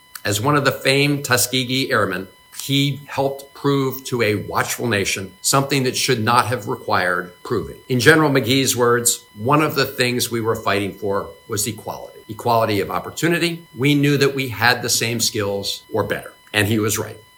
Democratic U.S. Senator Chris Van Hollen honored the accomplishments of Brigadier General Charles McGee as part of a memorial unveiling for the late Tuskegee Airman hosted by the Distinguished Flying Cross Society at the public library in Silver Spring that bears his name.
The Maryland Senator said in his comments that McGee’s efforts broke barriers in the military…